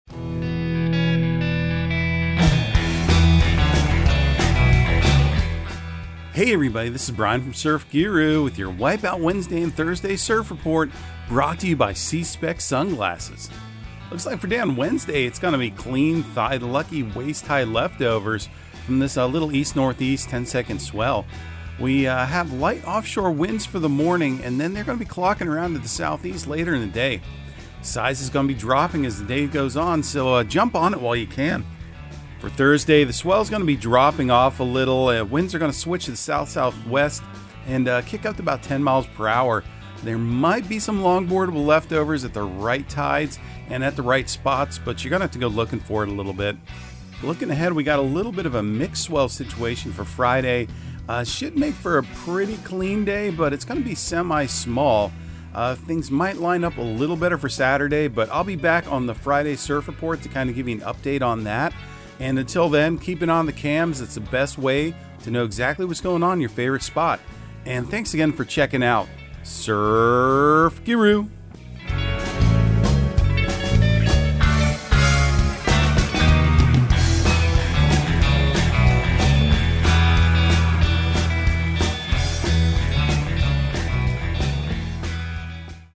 Surf Guru Surf Report and Forecast 01/18/2023 Audio surf report and surf forecast on January 18 for Central Florida and the Southeast.